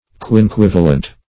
Search Result for " quinquivalent" : The Collaborative International Dictionary of English v.0.48: Quinquivalent \Quin*quiv"a*lent\, a. [Quinque- + L. valens, -entis, p. pr.